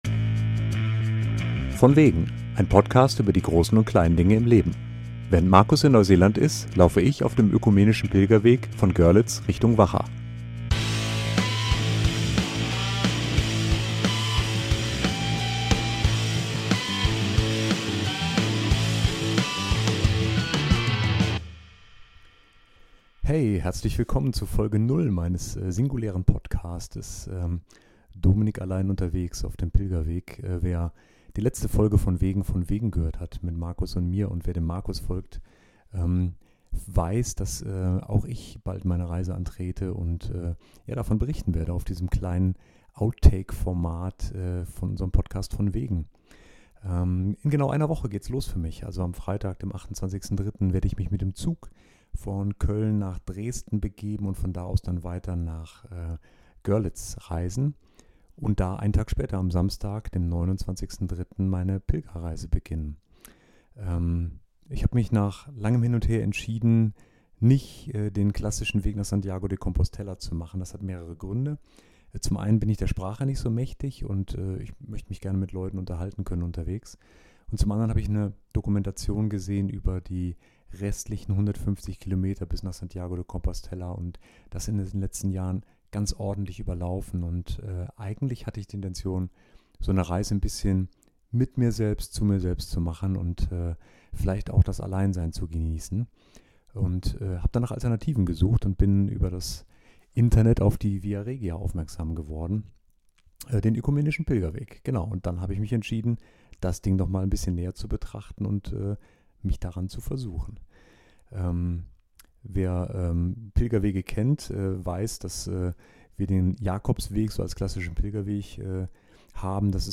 In dieser Folge 0 spreche ich über den Weg als solches und meine Intention ihn zu laufen. Ganz nebenbei möchte ich das kleine Aufsteck-Mikrofon und den Produktions-Workflow auf dem kleinen Smartphone-Display vor Abreise einmal durchgespielt haben Mehr